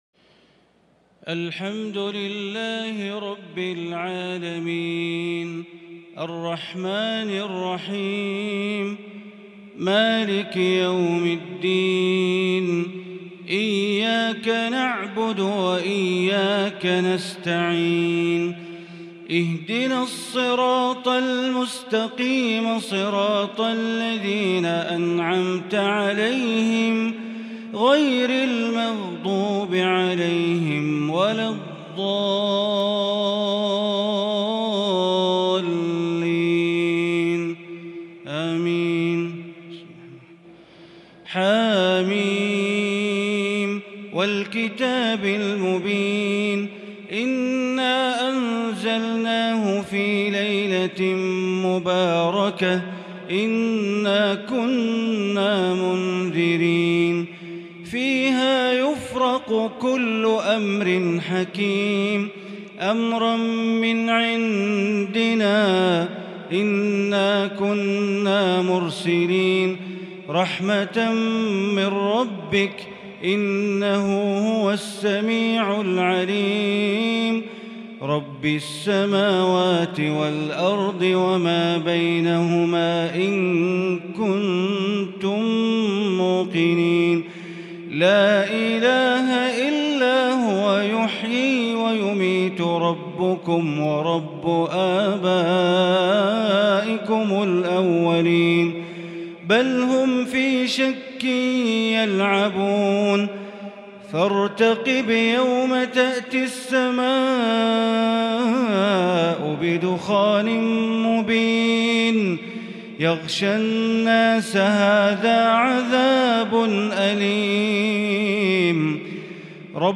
صلاة التراويح l ليلة 27 رمضان 1442 l من سور الدخان و الجاثية و الأحقاف| taraweeh prayer The 27th night of Ramadan 1442H | from surah Ad-Dukhaan and Al-Jaathiya and Al-Ahqaf > تراويح الحرم المكي عام 1442 🕋 > التراويح - تلاوات الحرمين